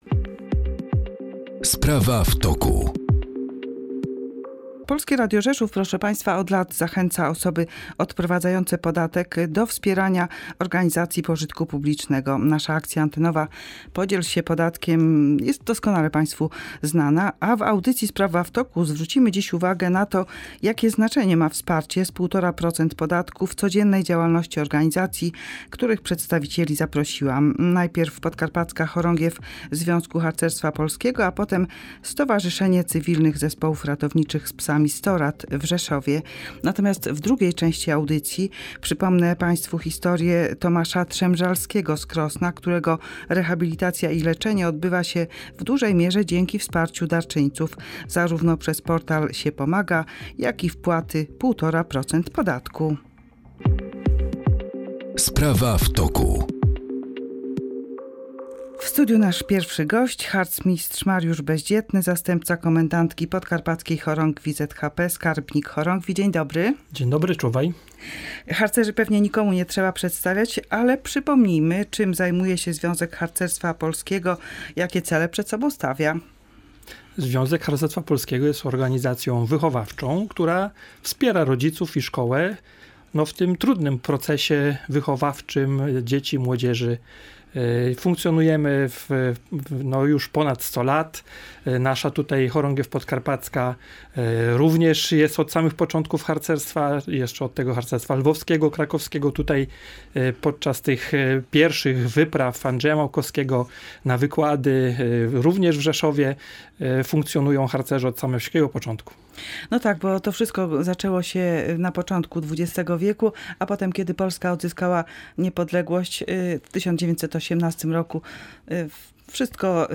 Do wysłuchania rozmów z gośćmi zaprasza